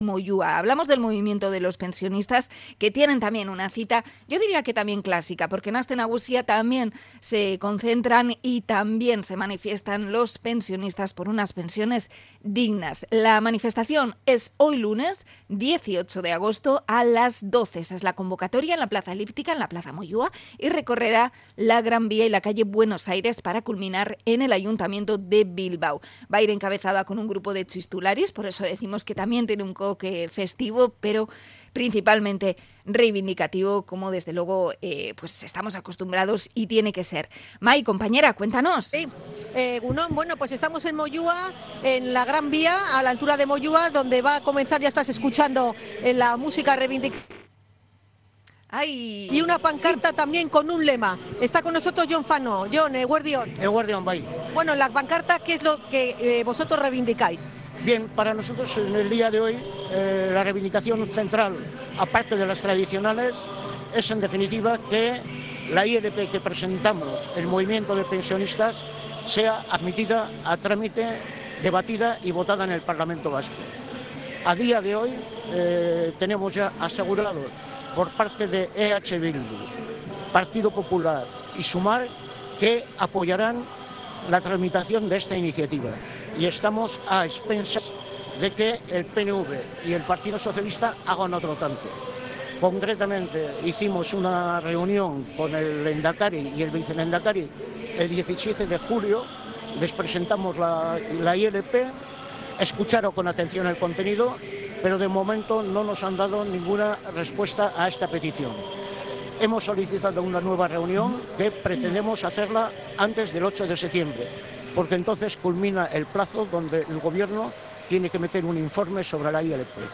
En declaraciones en directo a EgunON Magazine